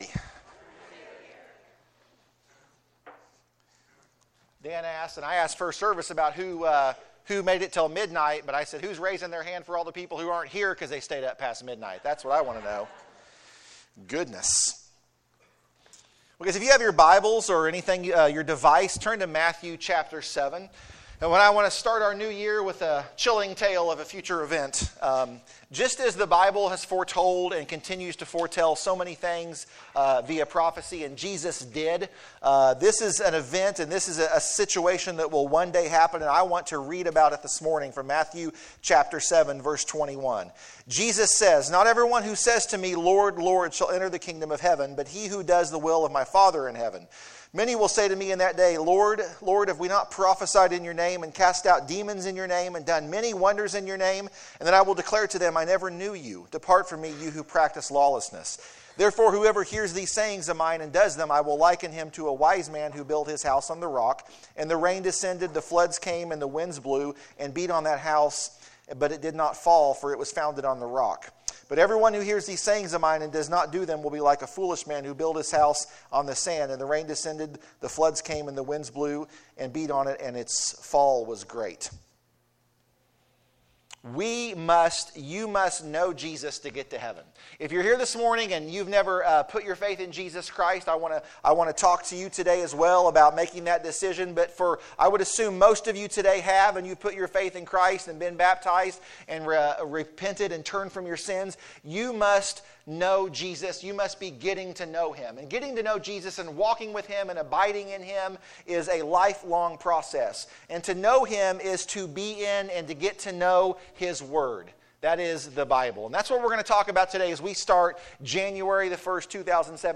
The sermon is profound and truth.